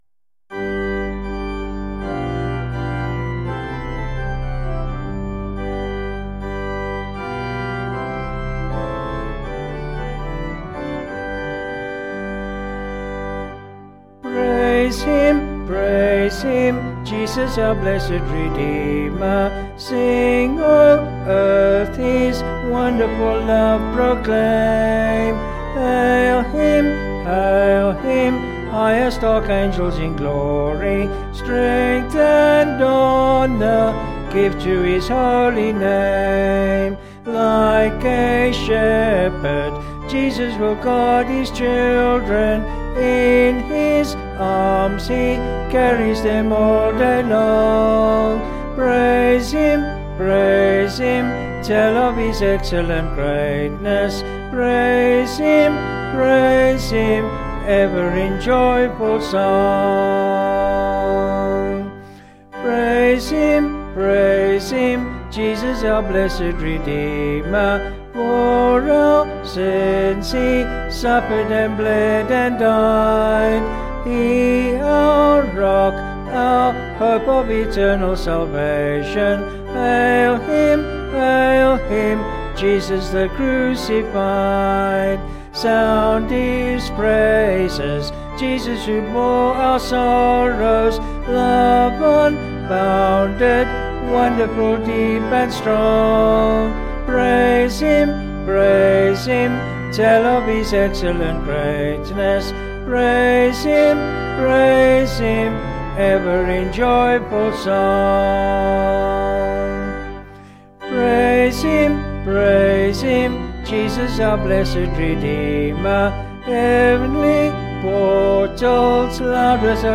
Vocals and Organ   264.6kb Sung Lyrics